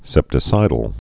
(sĕptĭ-sīdl)